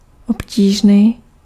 Ääntäminen
US : IPA : [hɑɹd]